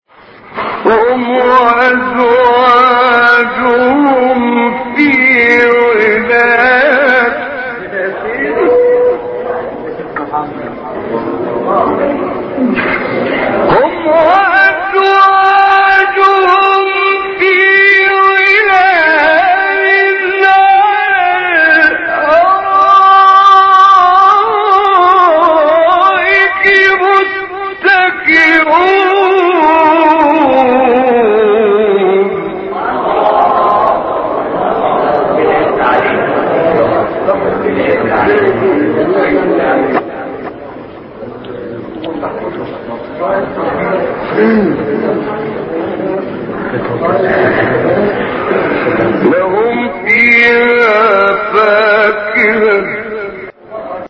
مقام : سه گاه